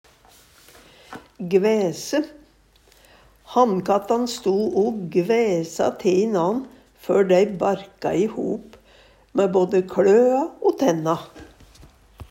gvæse - Numedalsmål (en-US)